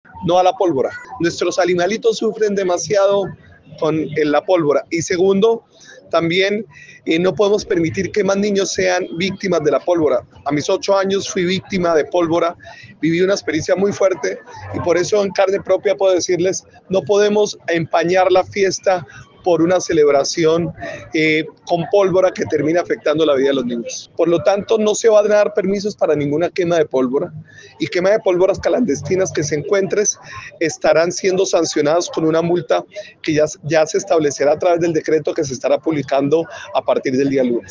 Jaime Andrés Beltrán, alcalde de Bucaramanga